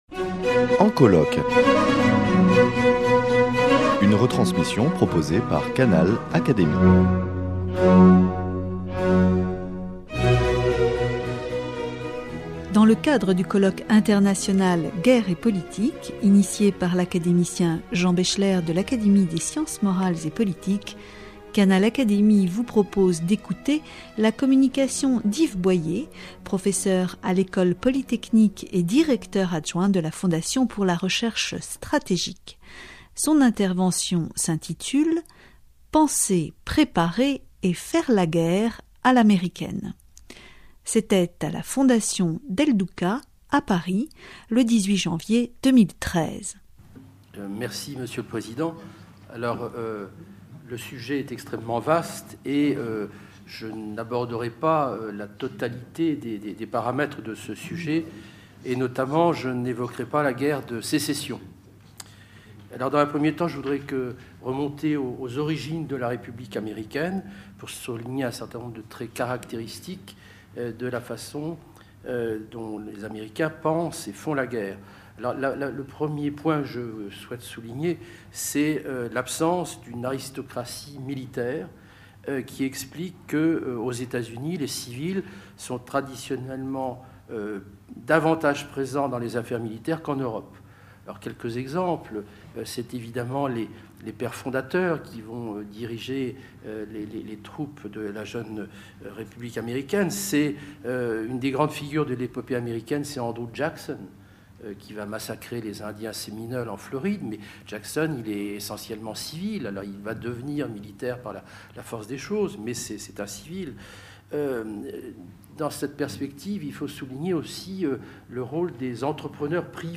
L’Académie des sciences morales et politiques sous la direction du sociologue Jean Baechler, membre de l'Institut, a organisé en effet, un colloque international sur le thème de la Guerre et de la société le 17, 18 et 19 janvier 2013, grâce au soutien de La Fondation Simone et Cino Del Duca, abritée au sein de l’Institut de France.